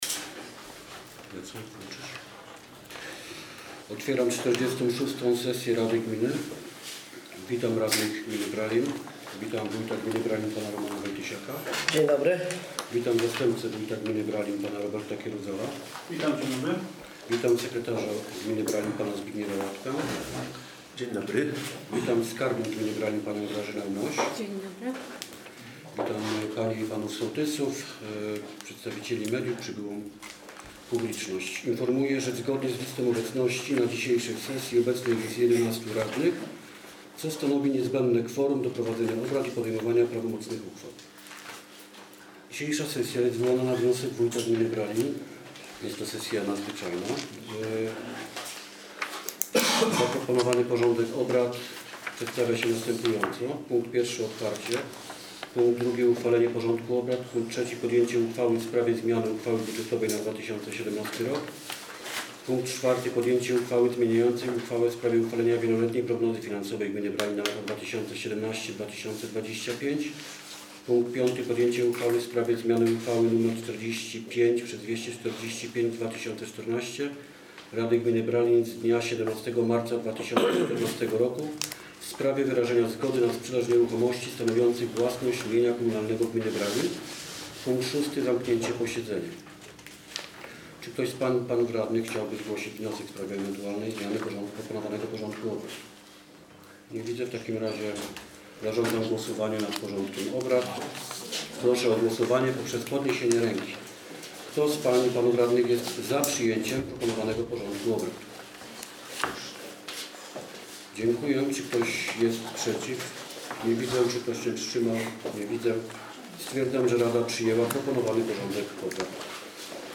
Nagranie: 46 sesja rady - 12 czerwca 2017 r.